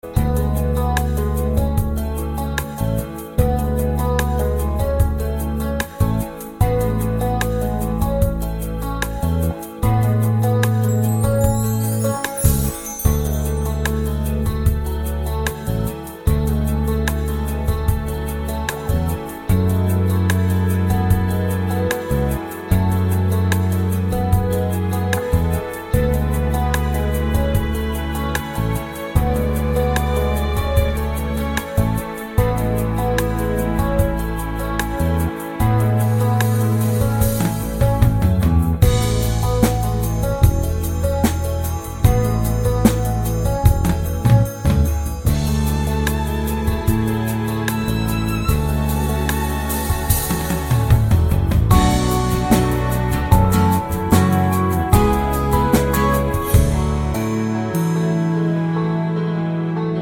no Backing Vocals Pop (1970s) 4:15 Buy £1.50